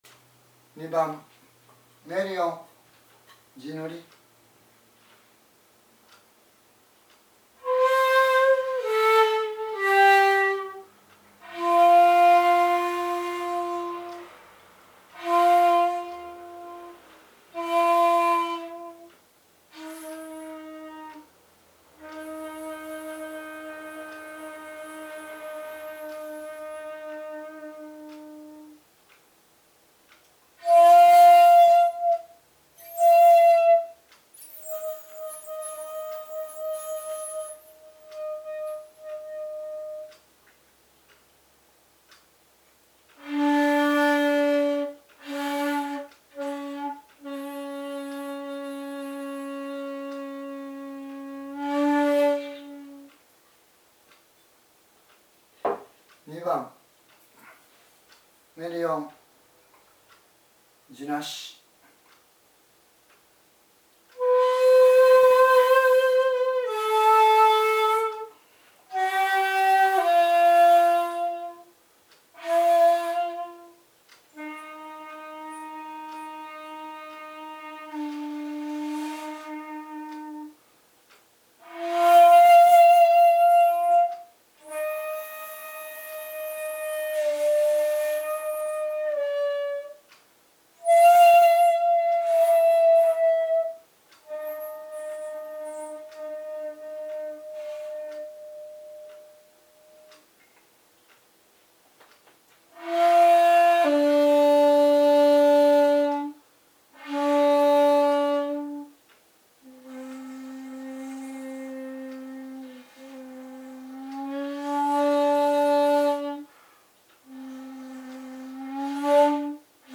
今回は如何に地無し管で琴古流本曲が生き返ってくるか、を地塗り管と実際に吹き比べてみたいと思います。
２．ツﾒﾘをはじめ、各メリ音が容易に出すことができる。